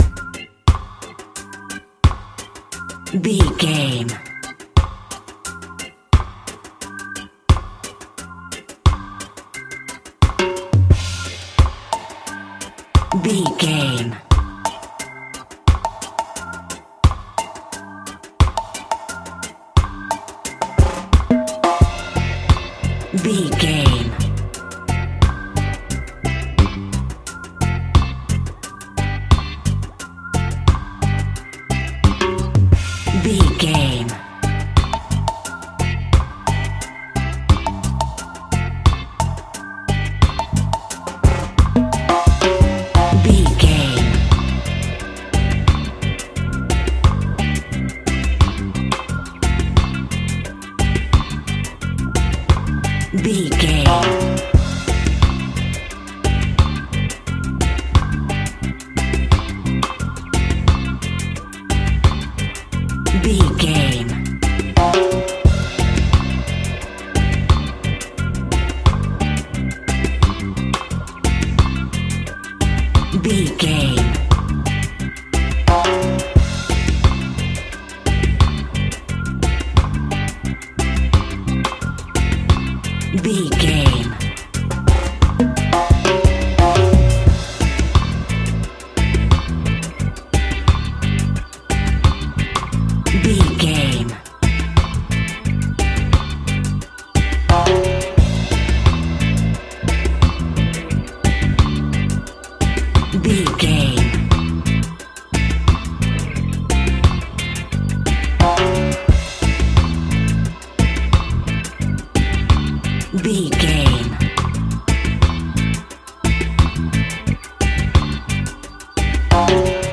Aeolian/Minor
cheerful/happy
mellow
drums
electric guitar
percussion
horns
electric organ